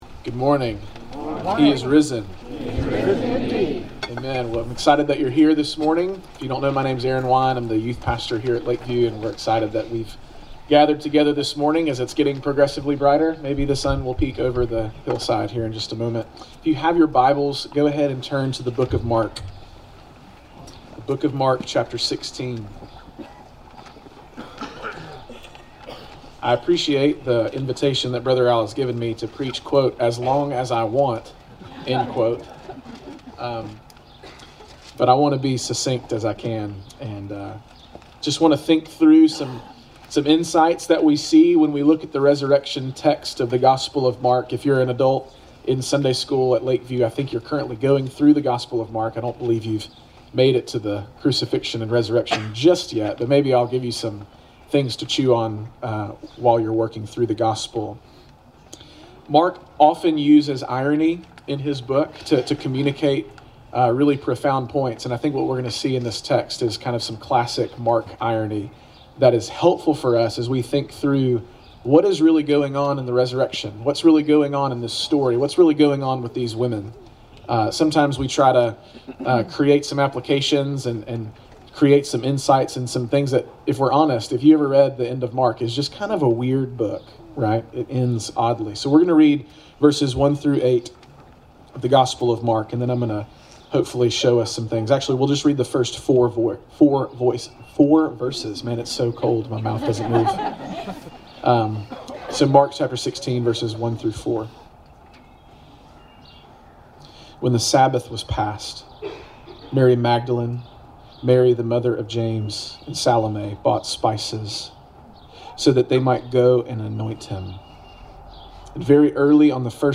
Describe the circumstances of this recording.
Easter 2019 Sunrise Service